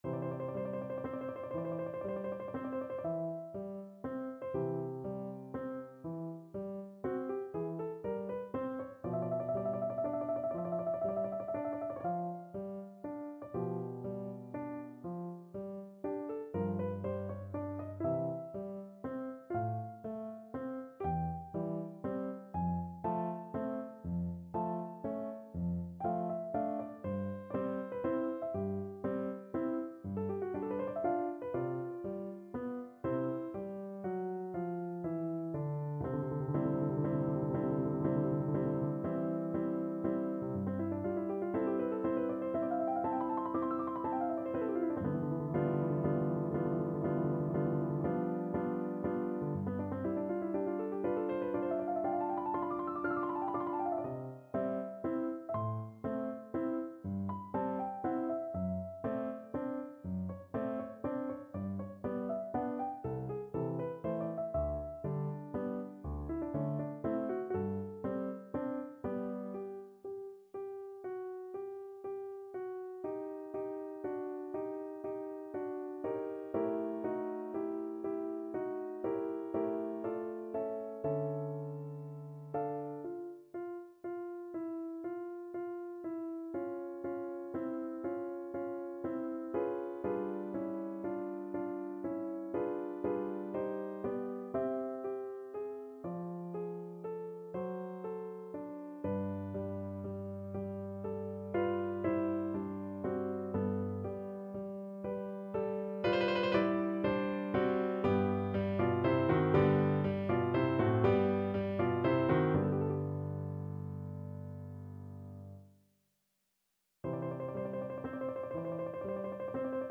No parts available for this pieces as it is for solo piano.
9/8 (View more 9/8 Music)
Adagio grazioso
Classical (View more Classical Piano Music)